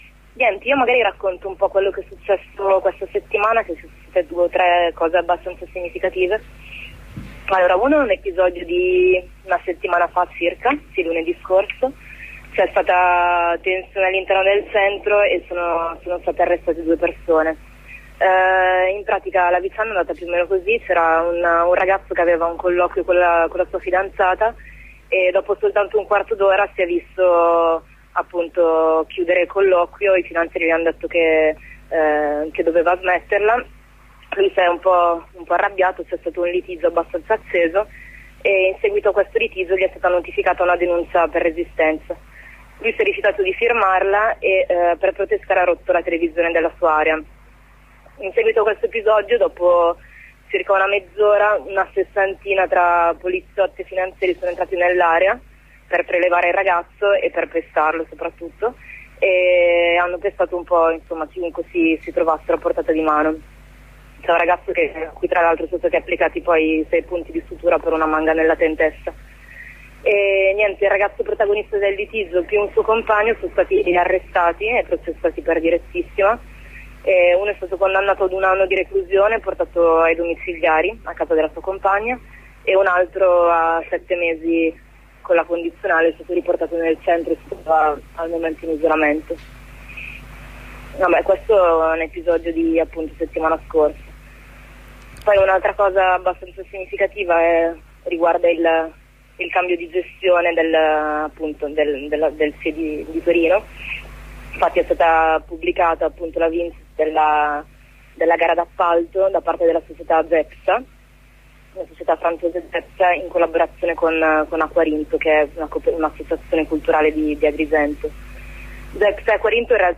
Nel corso della trasmissione abbiamo contattato una compagna di Torino per farci aggiornare riguardo la situazione nel CIE di Corso Brunelleschi.